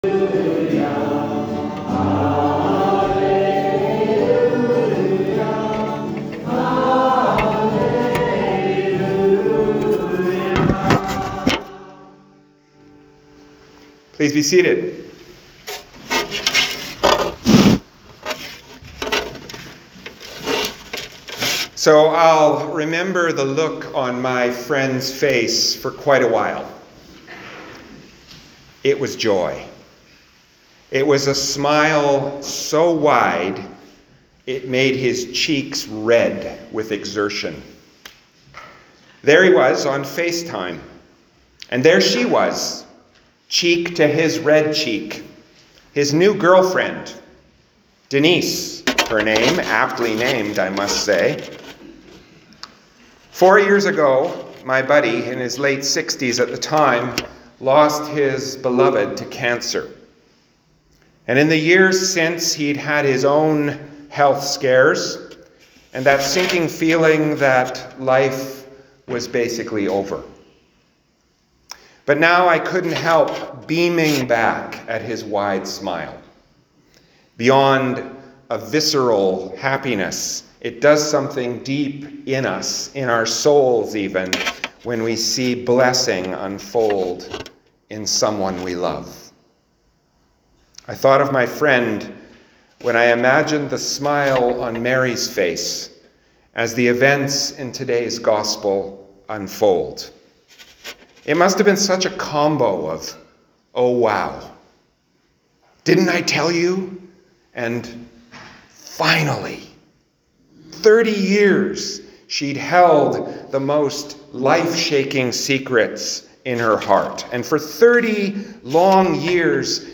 Sermons | St Philip Anglican Church